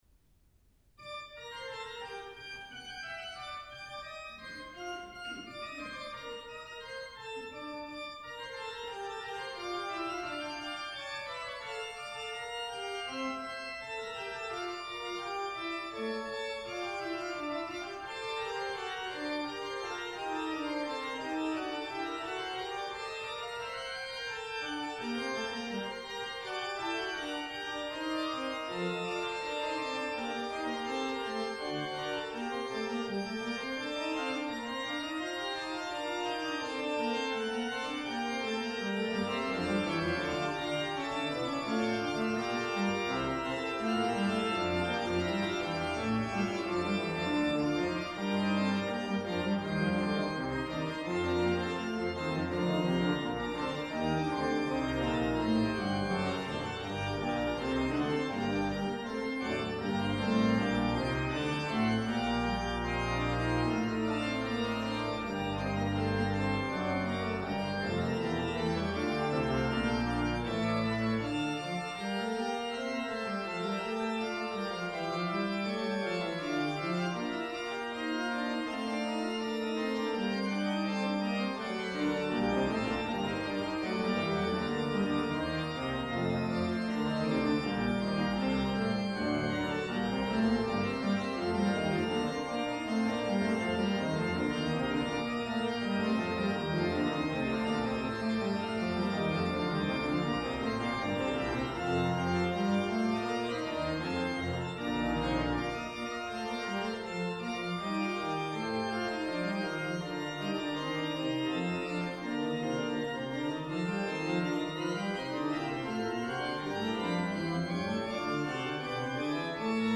Ålands orgelfestival 2011 - inspelningar
Mariehamn